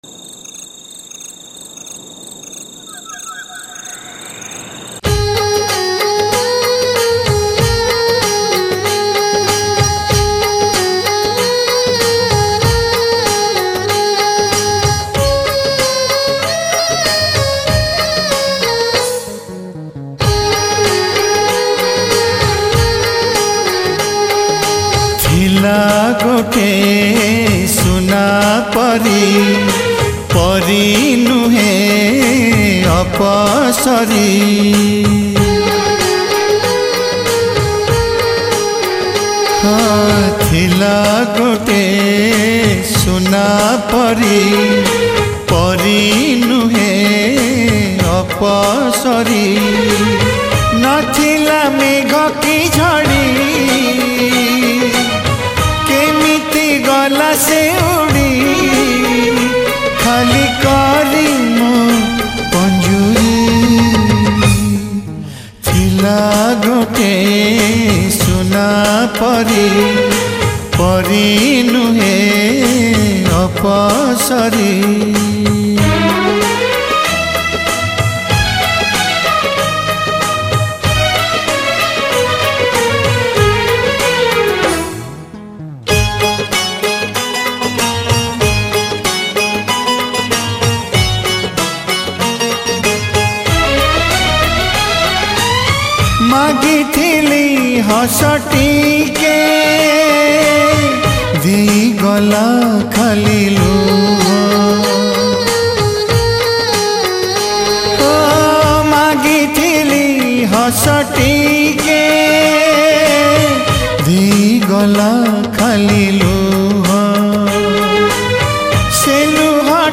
Odia Old Demand Album Sad Songs